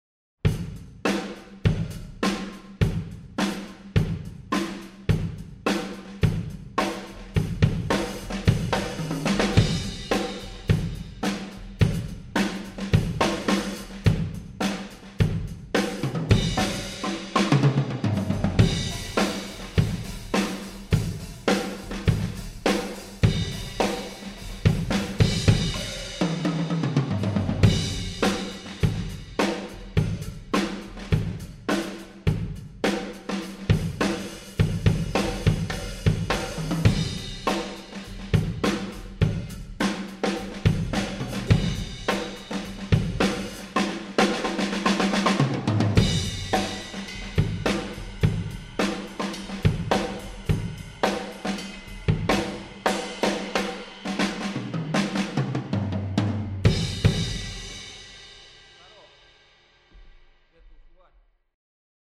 Не поленился, как есть, сделал из MS стерео трек...